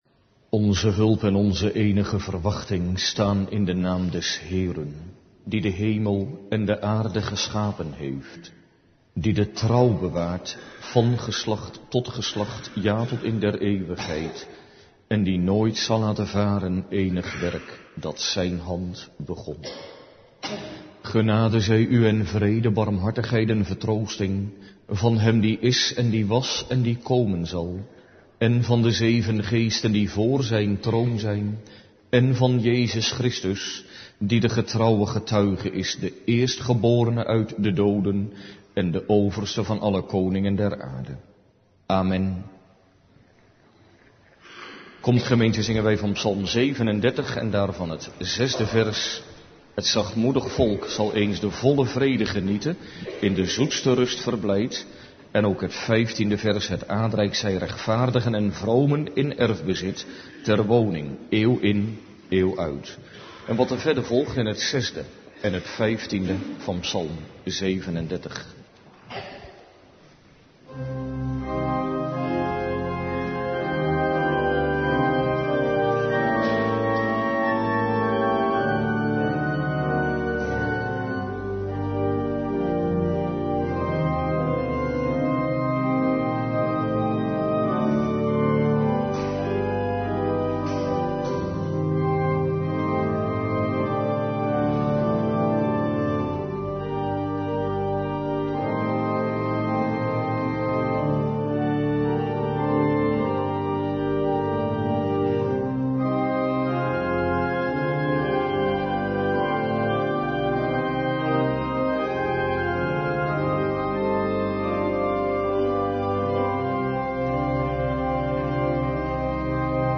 Kerkdienst